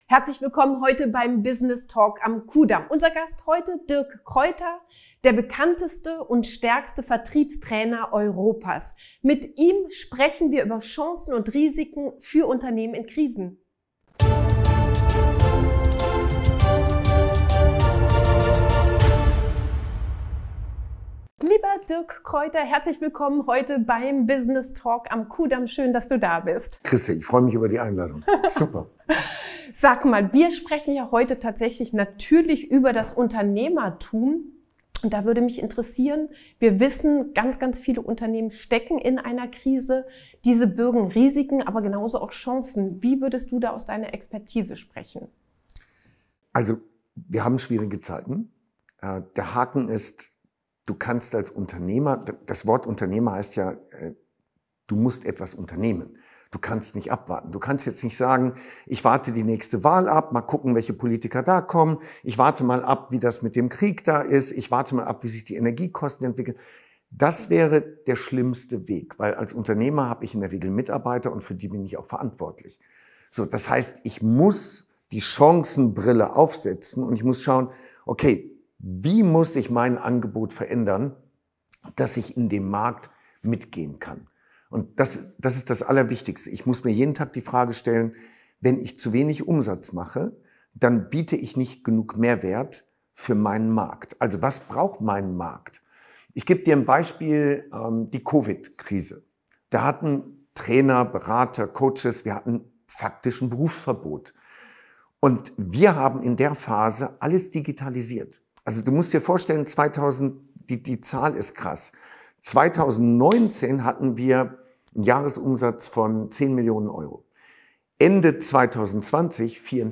im Interview erklärt, wie Unternehmer und Entscheiderinnen Krisen meistern und weshalb Firmen Probleme mit dem Vertrieb haben.